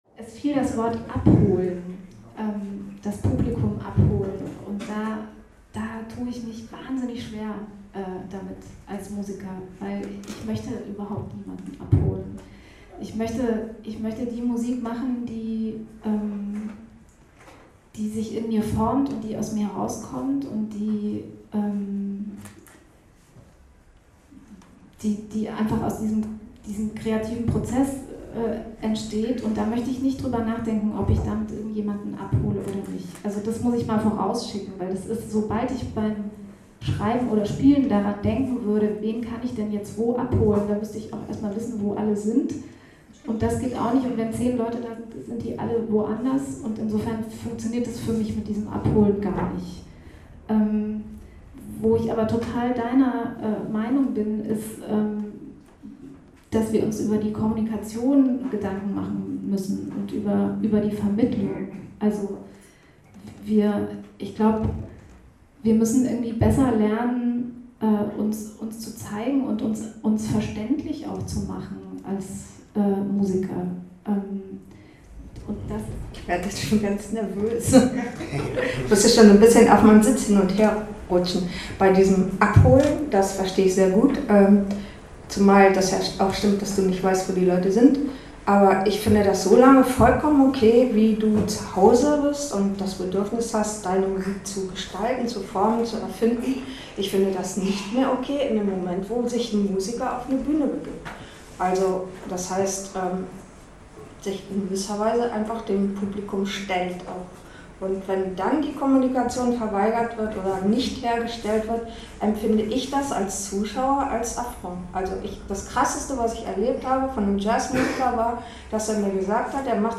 Jazz und Marketing
jazzwoche_dis_abholen.mp3